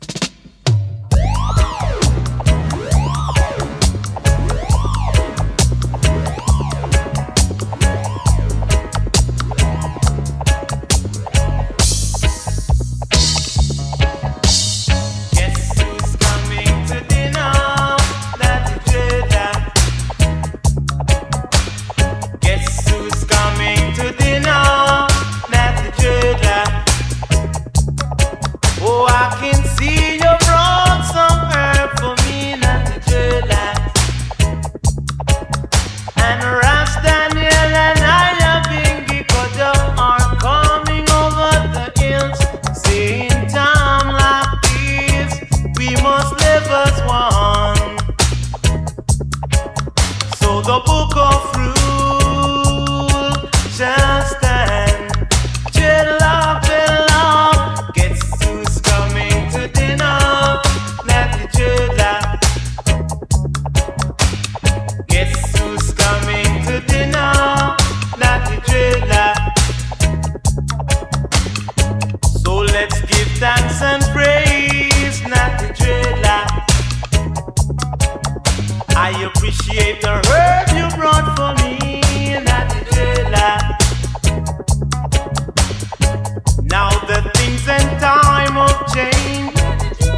SKA/ROCKSTEADY
オーセンティック・スカ大傑作！男泣きな最高メロディーの応酬！